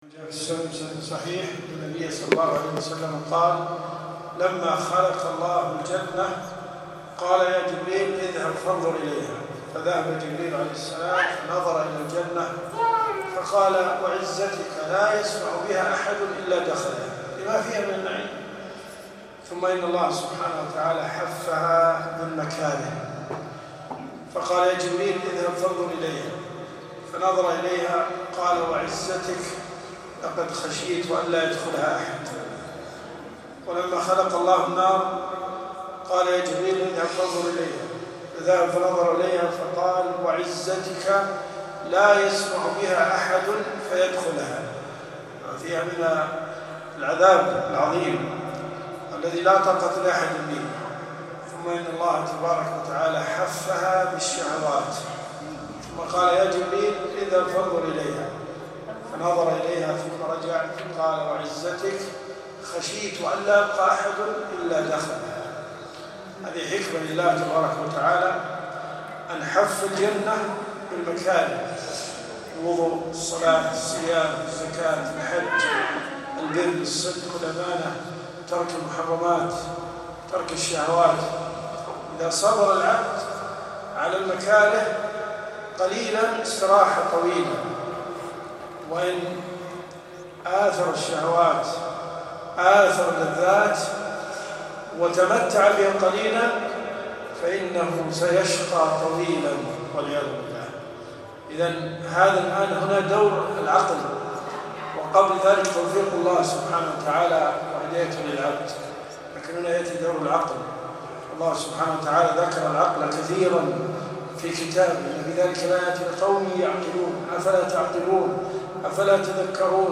كلمات المساجد . مغرب الخميس . وعزتك لا يسمع بها أحد إلا دخلها .